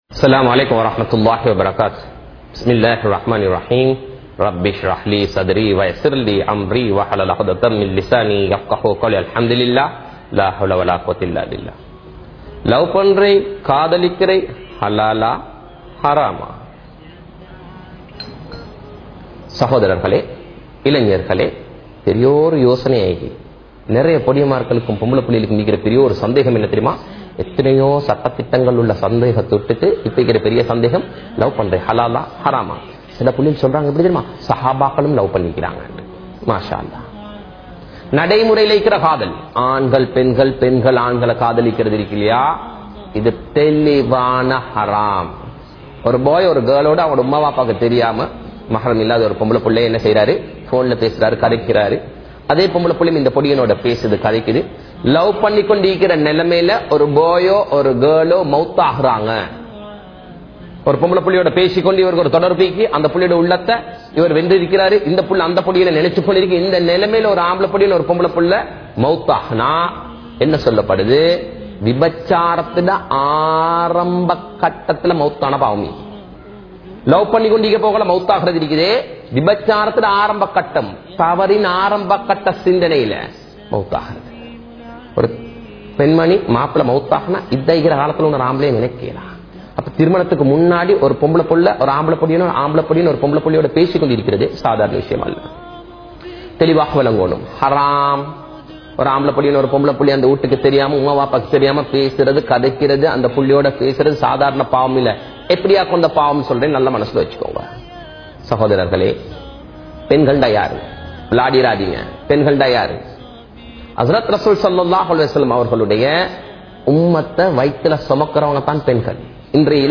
Kaathalippavarhale Aaafaththu (காதலிப்பவர்களே! ஆபத்து) | Audio Bayans | All Ceylon Muslim Youth Community | Addalaichenai